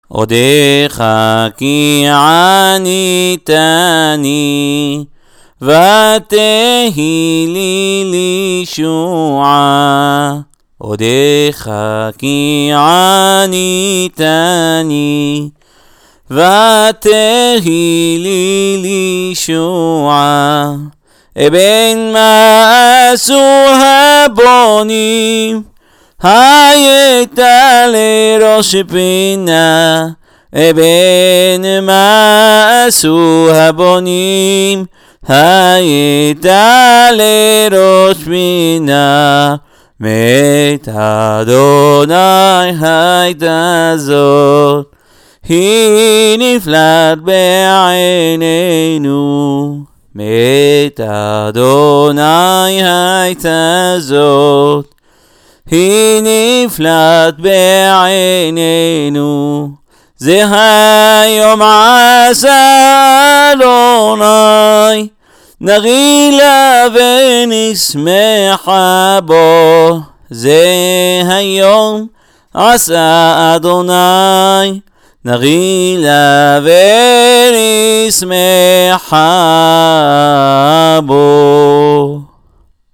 One particular section, Odekha Ki, is sung out loud with the whole congregation in various tunes.